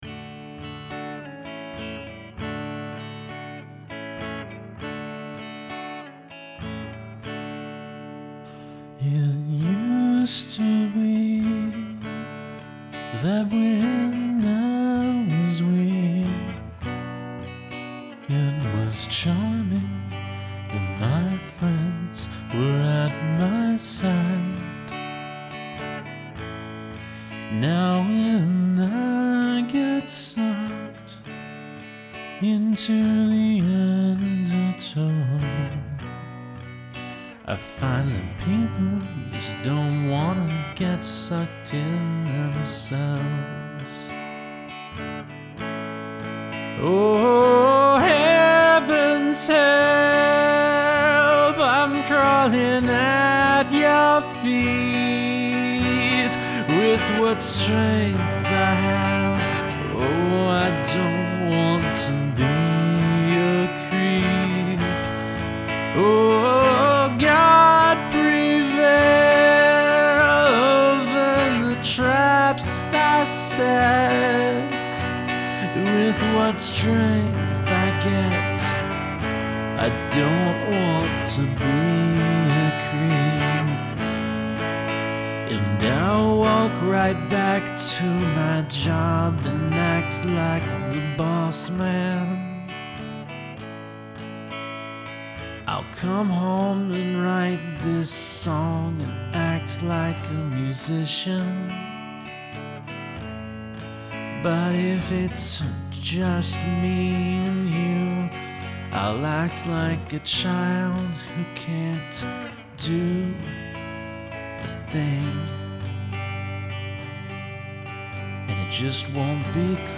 Nice harmonies, and I think the lyrics made me think a bit about my own situation, so I'd call that good.